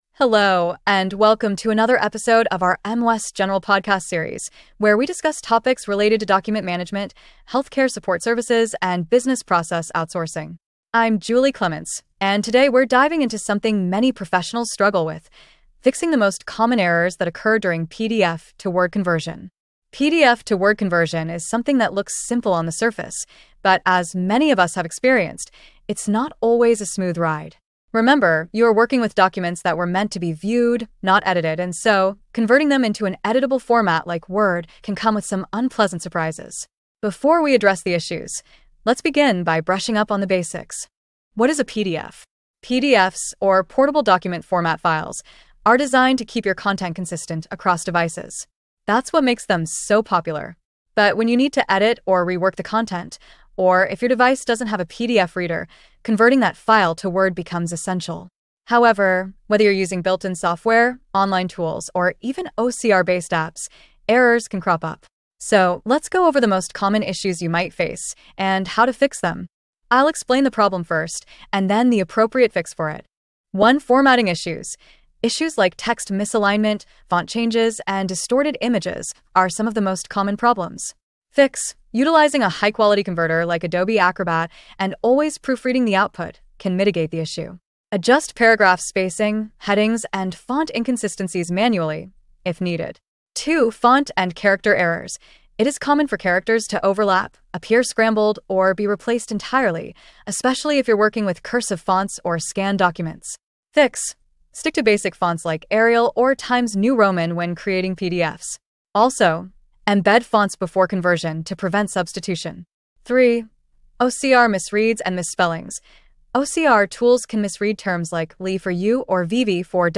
Disclaimer: This podcast uses an AI-generated voice to narrate content written by humans.